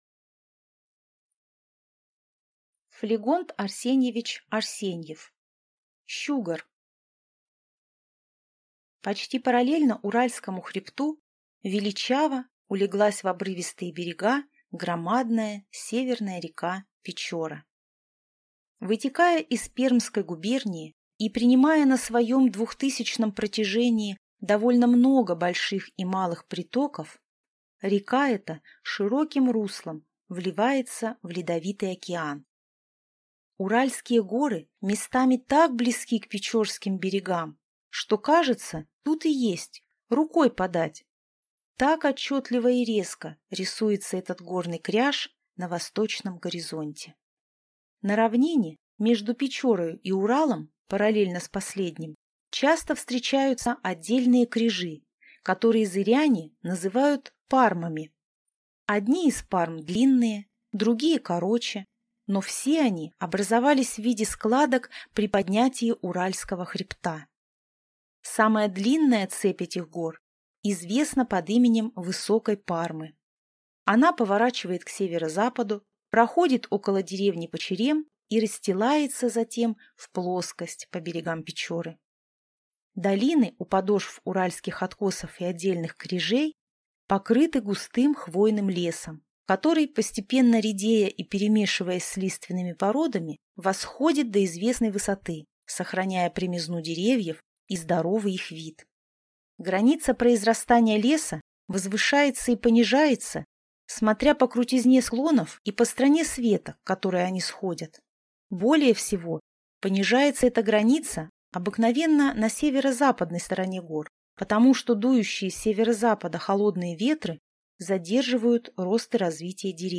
Аудиокнига Щугор | Библиотека аудиокниг